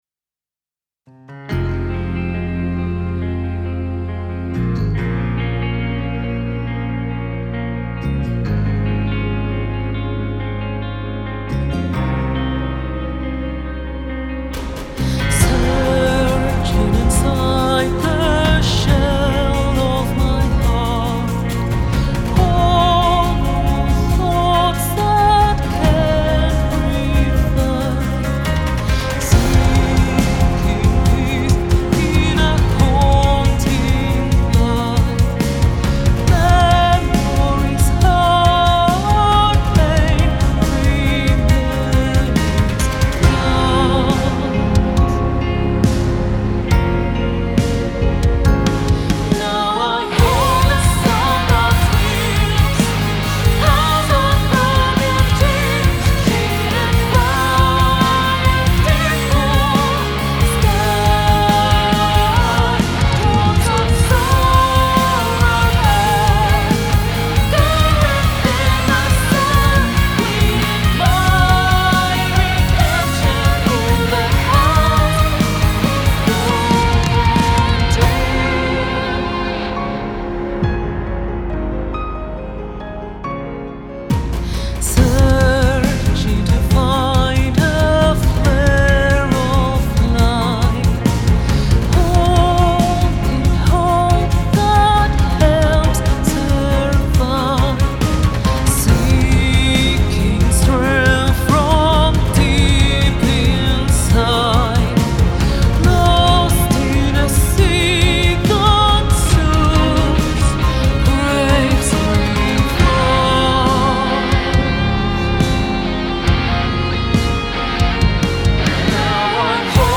Alternative Rock Song sucht guten Mix
Der Song hat eine BPM von 138, die an einer Stelle abweicht.
Projekt: The Dark Bridge Titel: House Of Broken Dreams stems 44.1 kHz/ 24 Bit / 138 BPM (bis auf eine Ausnahme) Stems (Version 1.01, Update Klavier und Solo Cello) Stems (nur Solo Cello und Klavier)